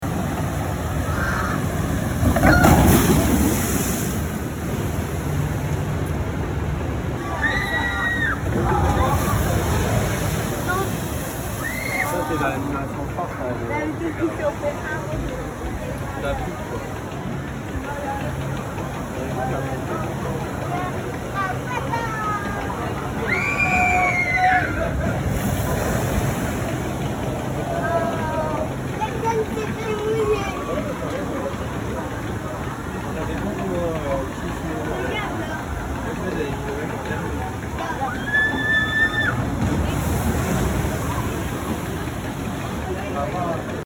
Звуки парка аттракционов
4. Звук водных американских горок